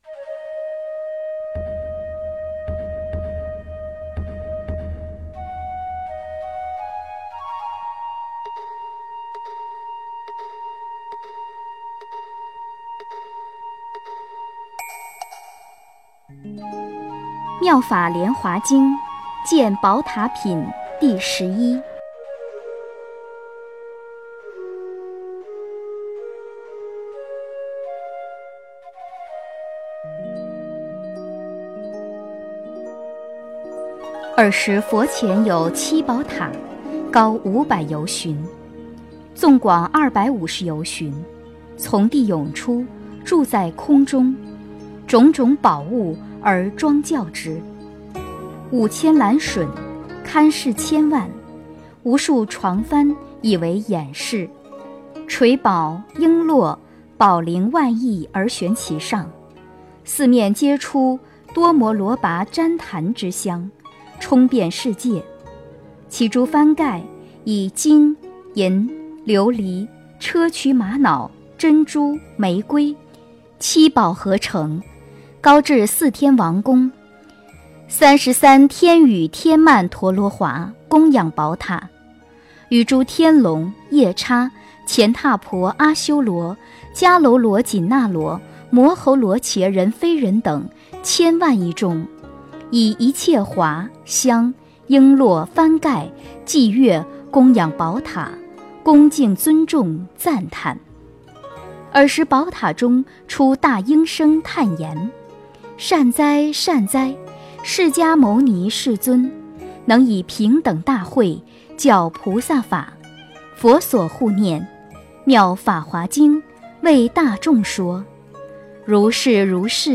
《妙法莲华经》见宝塔品第十一 诵经 《妙法莲华经》见宝塔品第十一--佚名 点我： 标签: 佛音 诵经 佛教音乐 返回列表 上一篇： 《妙法莲华经》授学无学人记品第九 下一篇： 金刚经：第七品和第八品 相关文章 弥勒佛--天使波罗蜜05 弥勒佛--天使波罗蜜05...